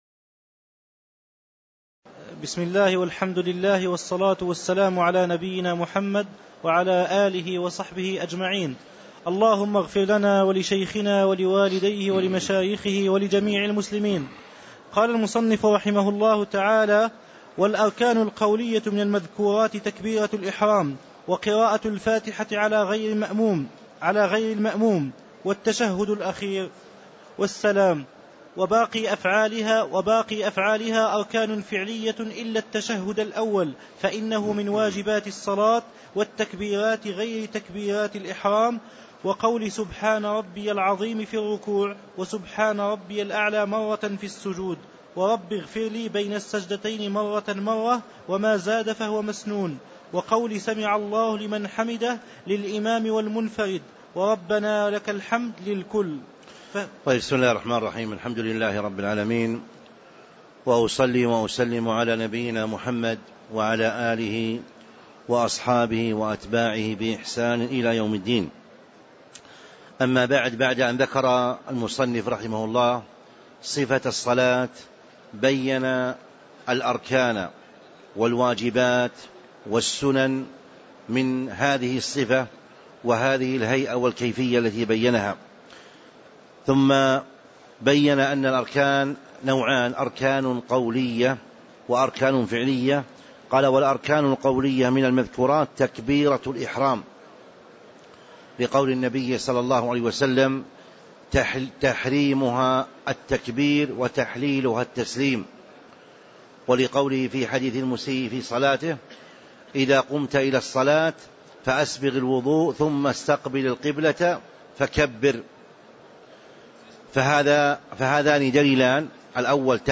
تاريخ النشر ١٩ رمضان ١٤٤٥ هـ المكان: المسجد النبوي الشيخ